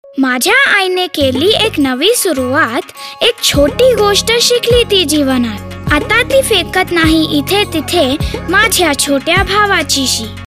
This public service announcement is one in a series for a two year campaign on sanitation, hygiene and judicious use of water.